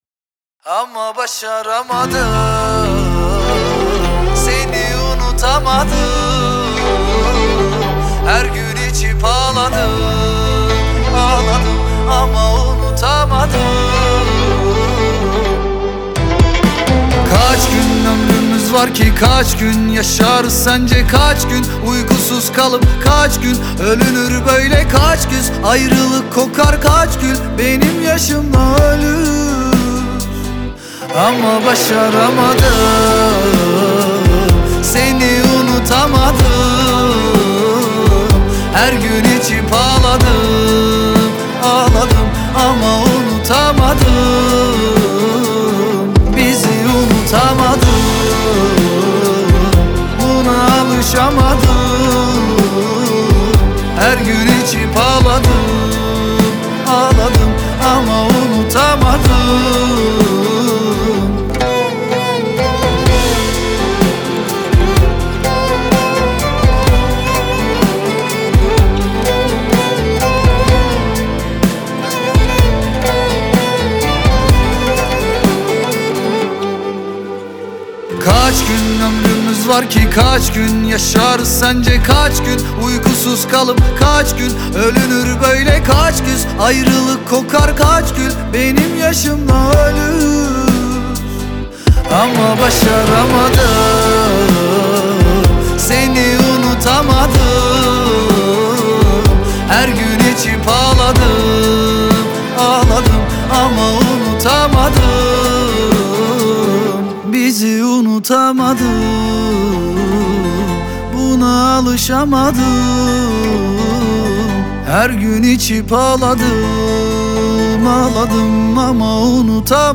Трек размещён в разделе Турецкая музыка / Поп.